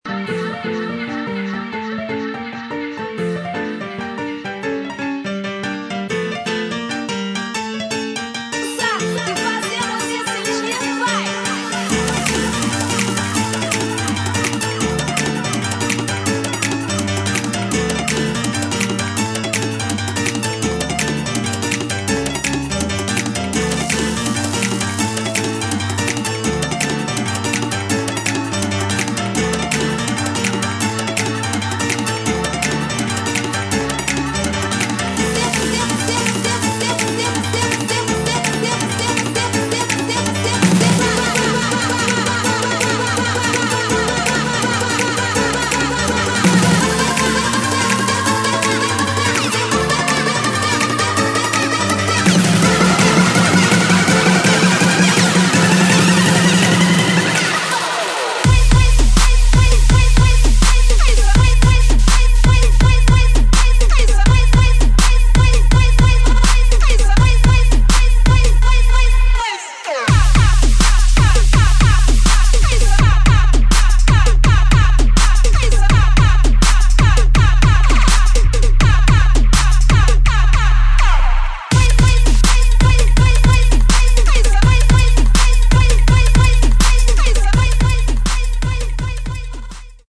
[ DRUM'N'BASS / JUNGLE / JUKE ]